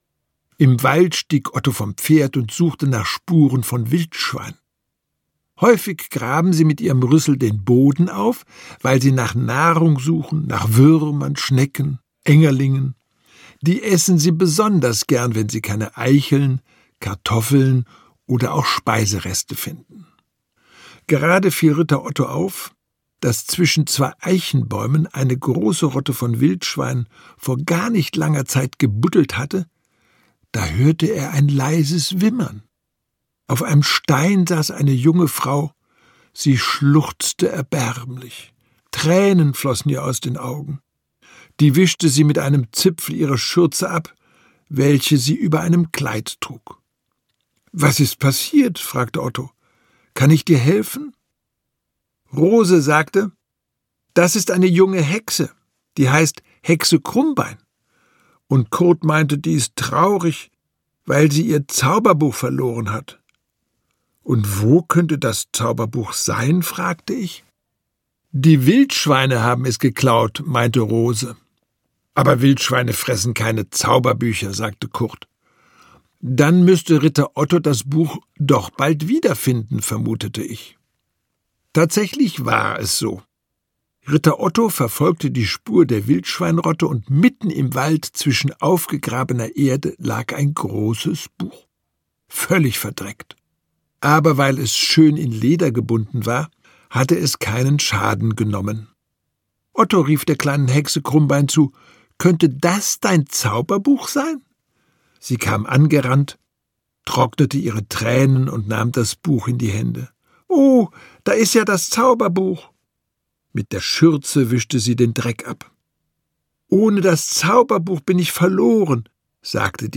Ritter Otto, eine Prinzessin, eine Hexe, ein Drache und ganz viel mehr ... - Ulrich Wickert - Hörbuch